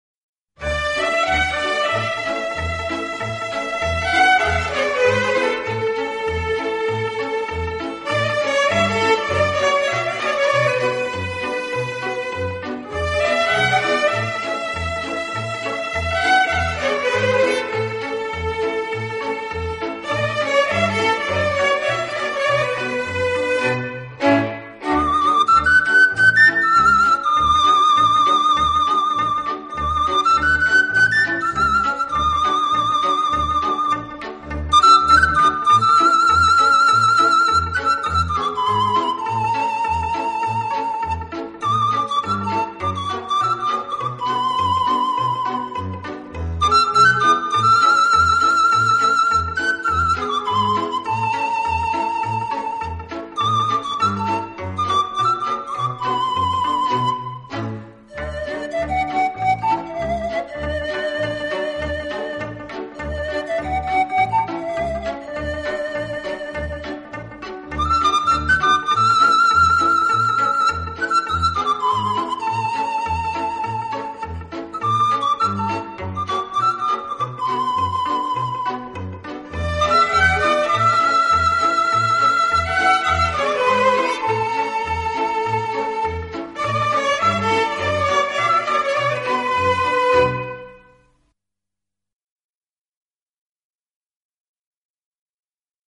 超广角音场的空间感演绎，大自然一尘不染的精华，仿佛让你远离凡尘嚣暄，
置身于世外桃园,尽情享受这天簌之音……